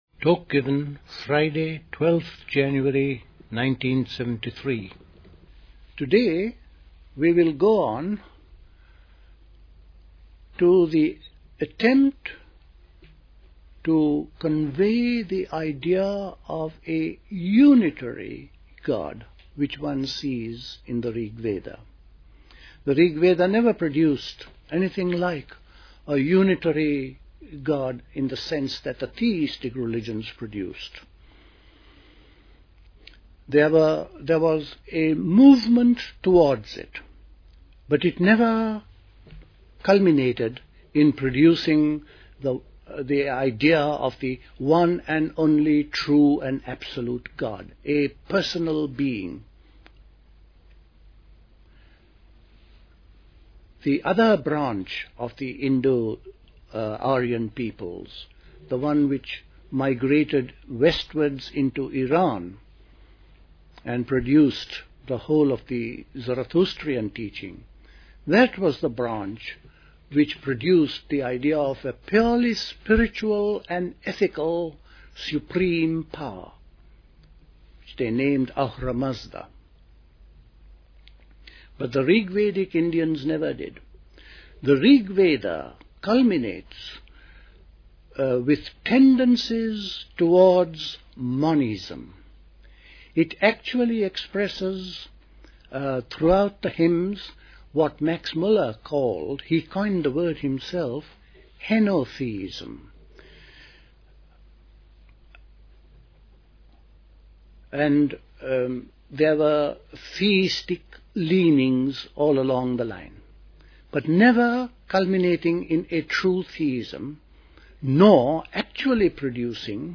A talk
Dilkusha, Forest Hill, London